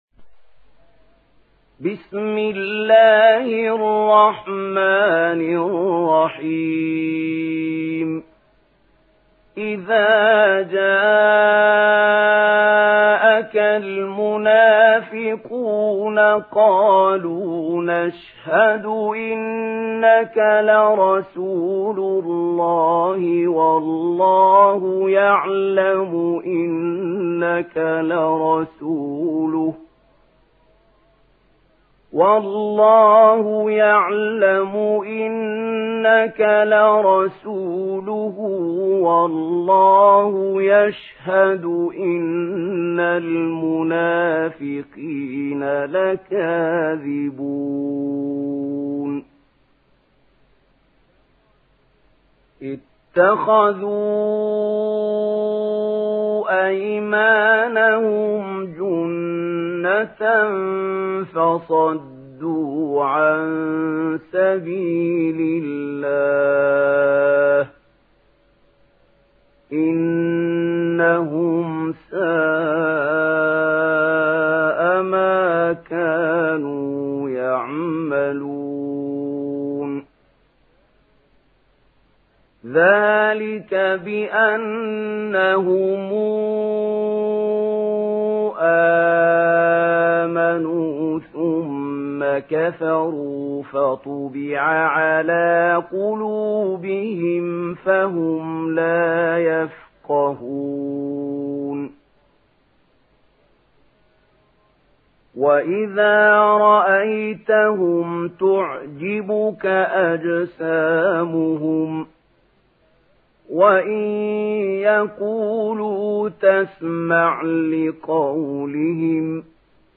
دانلود سوره المنافقون mp3 محمود خليل الحصري (روایت ورش)